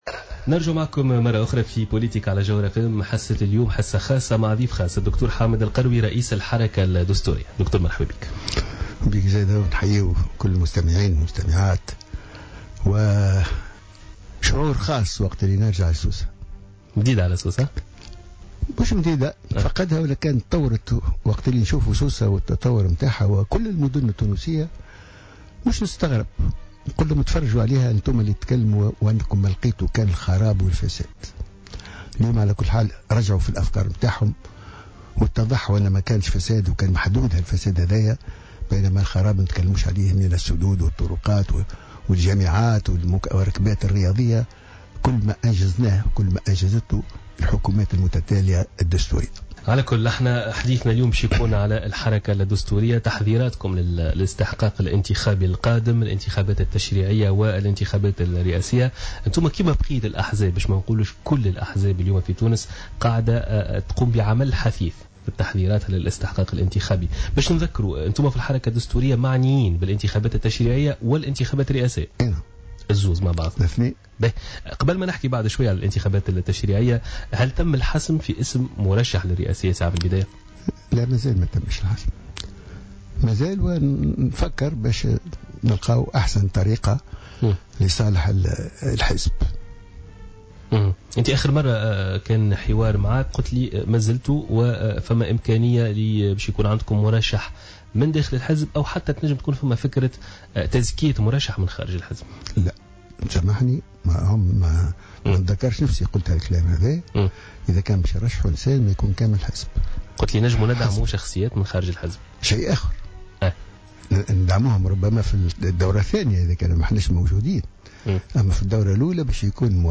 قال رئيس الحركة الدستورية حامد القروي في تصريح لجوهرة أف أم اليوم الإثنين إن من كان يتهم الحكومات "الدستورية" السابقة بالفساد والخراب اتضحت لهم اليوم قيمة الانجازات التي حققتها هذه الحكومات من جامعات ومستشفيات ومركبات رياضية وبنية تحتية على حد قوله.